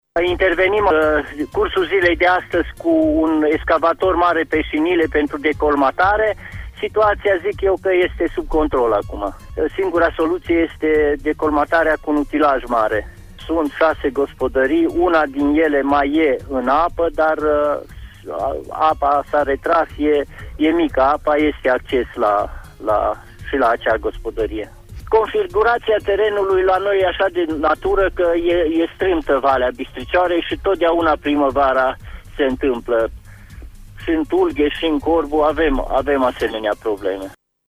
Singura solutie este decomatarea albiei cu un utilaj mare, a declarat primarul comunei Corbu, Nicușor Drugă, la emisiunea Pulsul Zilei:
primar-corbu.mp3